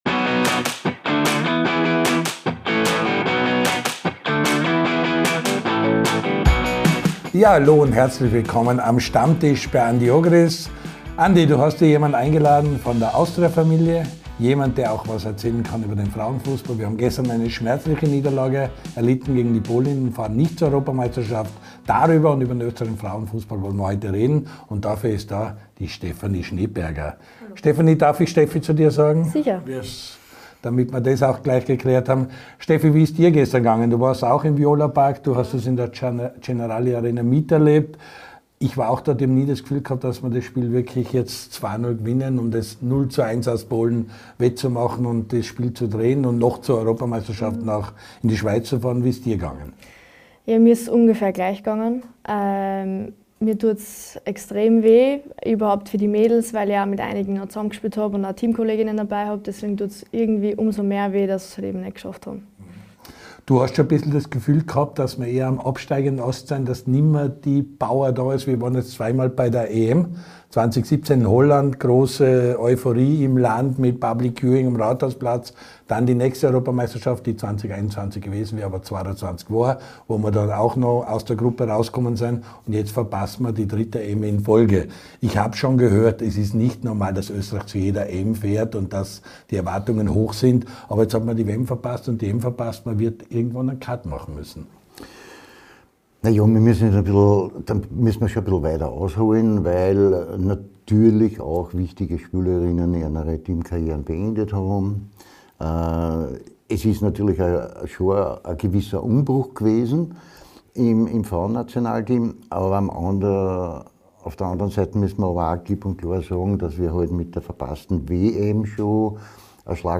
Der LAOLA1-Kult-Talk von und mit Andy Ogris!